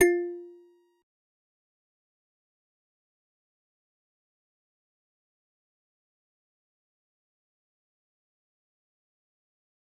G_Musicbox-F4-pp.wav